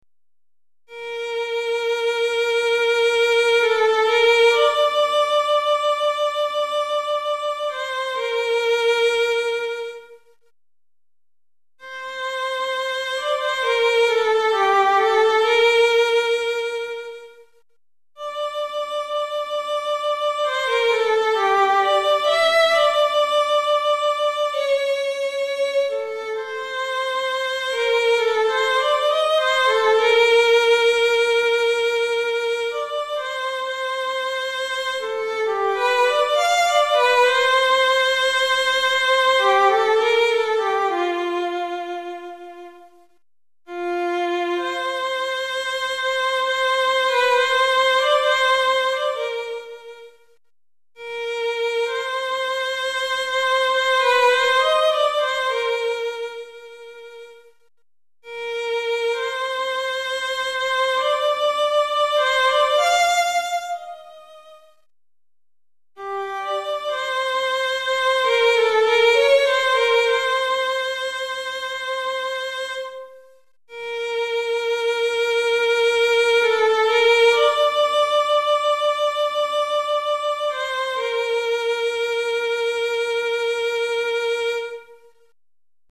Violon Solo